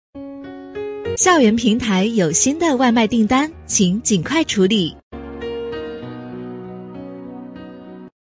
【女57号彩铃】语音播报
【女57号彩铃】语音播报.mp3